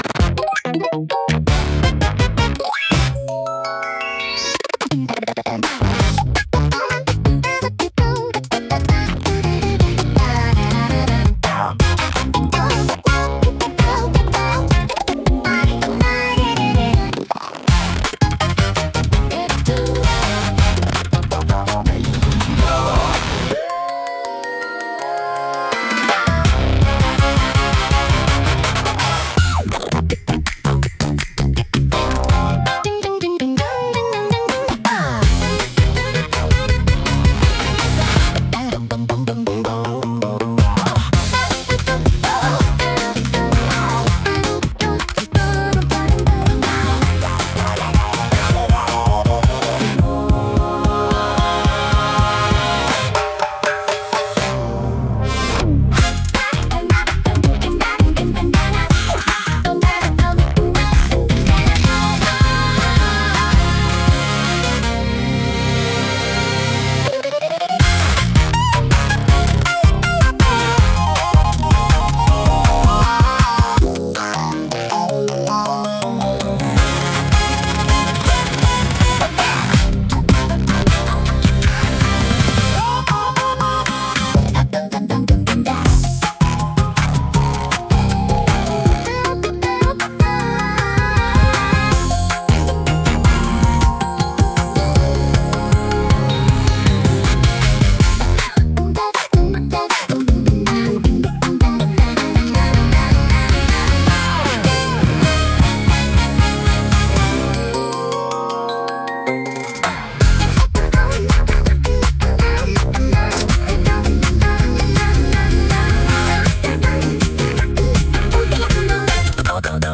イメージ：インスト,アバンギャルド・ポップ,エレクトロ・ポップ,グリッチポップ,謎
インストゥルメンタル（Instrumental）